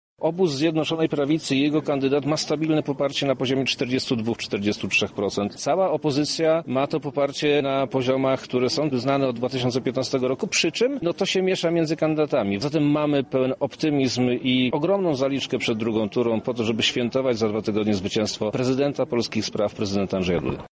Wieczór wyborczy w Radiu Centrum. Relacja z lubelskich sztabów
Mamy do czynienia z sytuacją, która jest znana w Polsce od 2015 roku– mówi poseł Prawa i Sprawiedliwości Przemysław Czarnek.